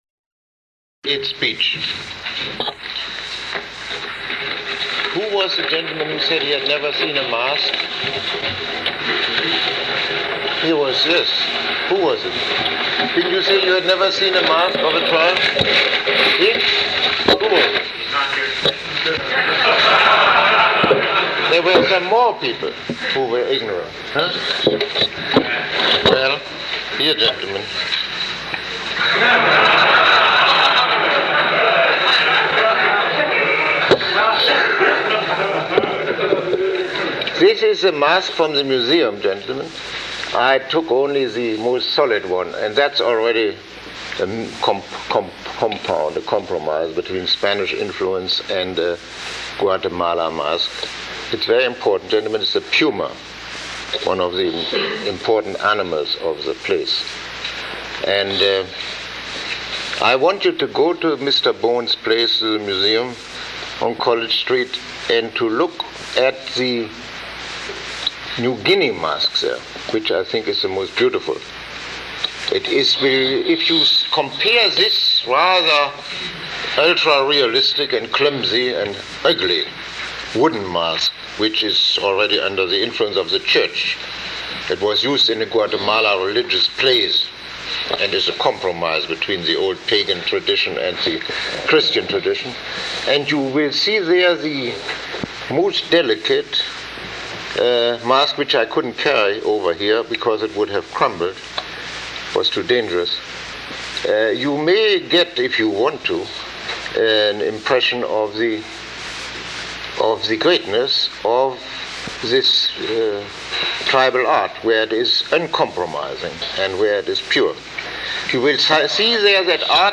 Lecture 10